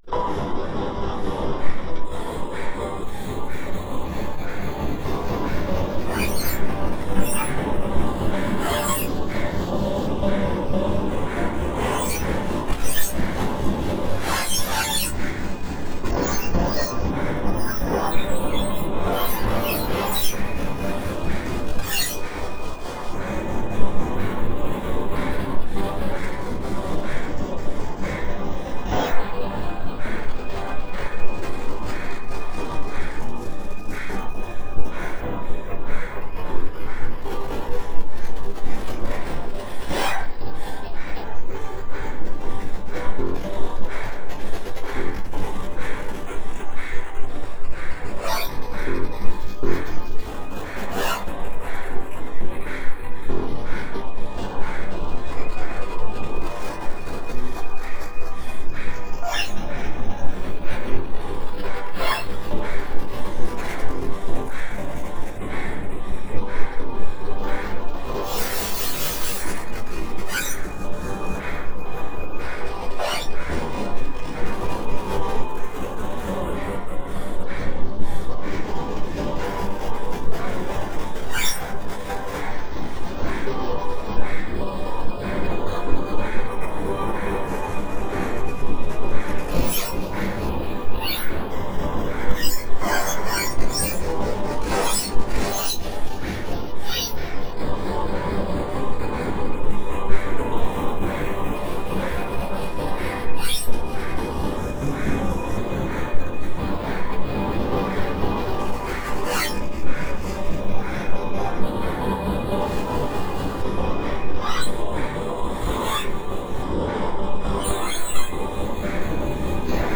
2000年から7年間の制作期間を経て完成した本作は、もはや使用楽器が何であるかの問すらも無意味なものにするほど加工され、